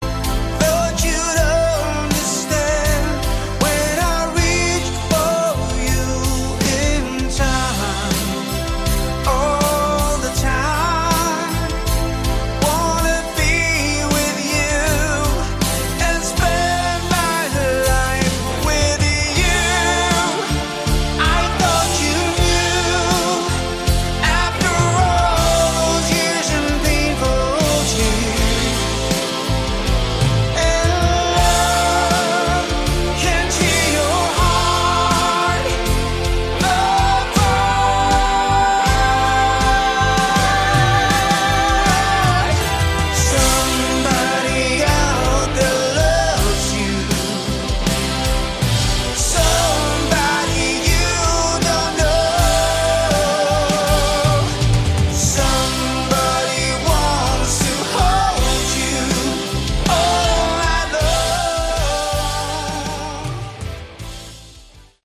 Category: Melodic Rock
vocals
bass
keyboards
lead and rhythm guitar
drums